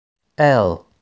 Alfabeto em Inglês Pronúncia a letra L
Alfabeto-em-Inglês-Pronúncia-a-letra-L.wav